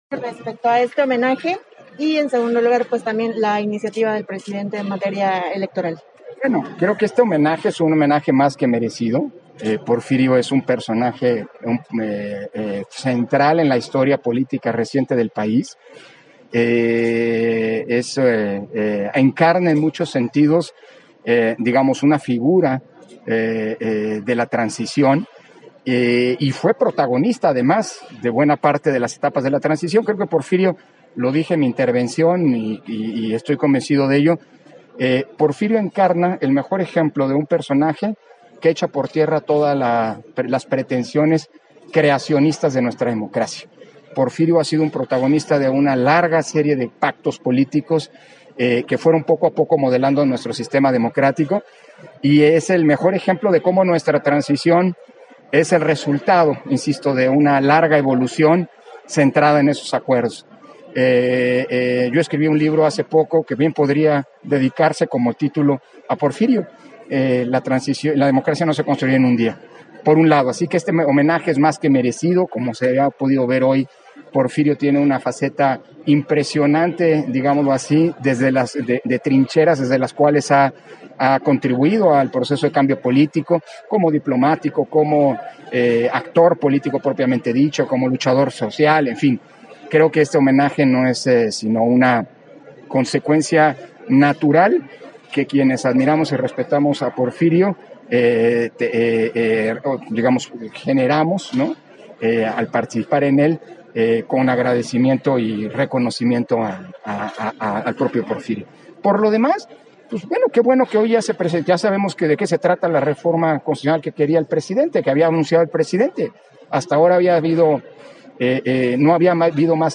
290422_AUDIO_ENTREVISTA-CONSEJERO-PDTE.-CÓRDOVA-AL-TÉRMINO-DE-SU-INTERVENCIÓN-EN-EL-HOMENAJE-A-PORFIRIO-MUÑOZ-LEDO - Central Electoral